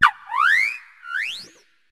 palafin_ambient.ogg